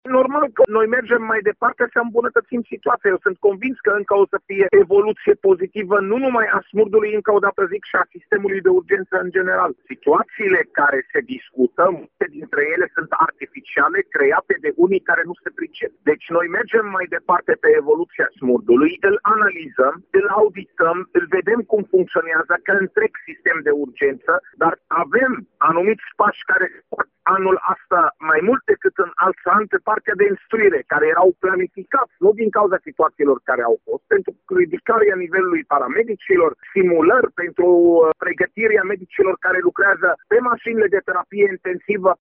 În acest an se va insista pe pregătirea personalului de specialitate, mai ales a celor care lucrează pe elicoptere, spune Raed Arafat: